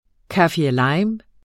Udtale [ kɑfiɐ̯ˈlɑjm ]